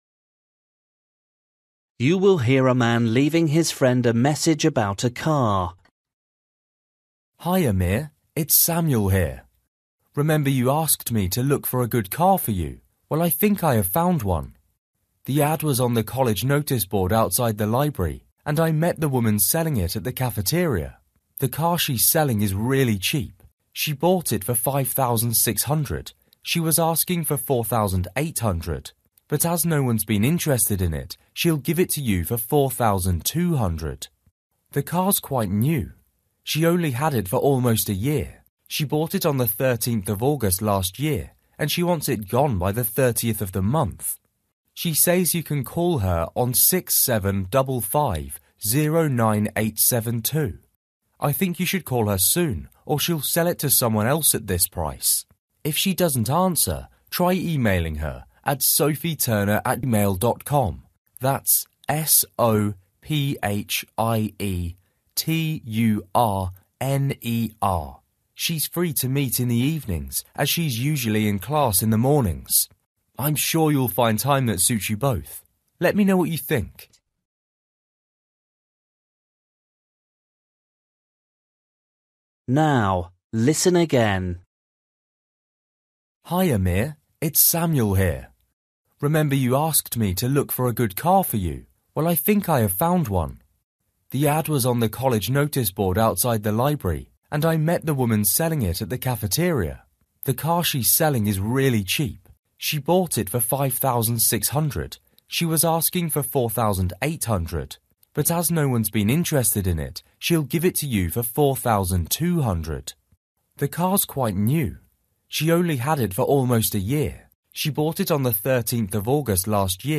You will hear a man leaving his friend a message about a car.